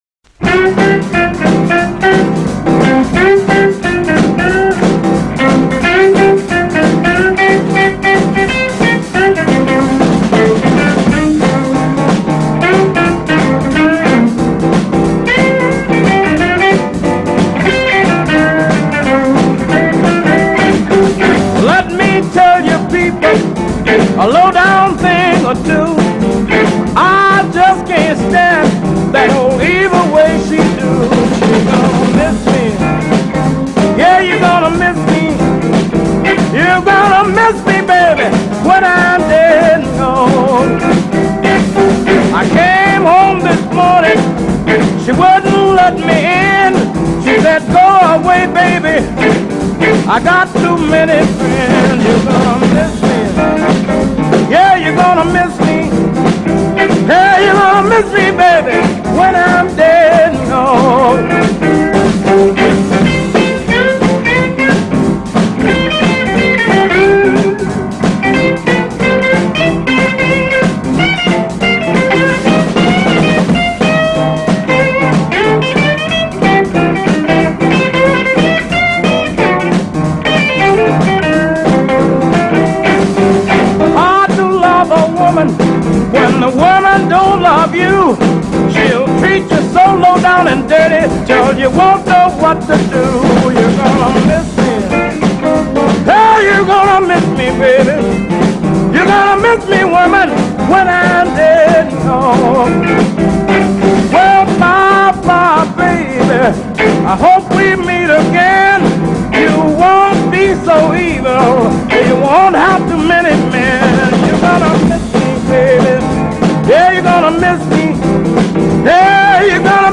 بلوز الکتریک کلاسیک
Blues